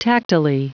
Prononciation du mot tactilely en anglais (fichier audio)
Prononciation du mot : tactilely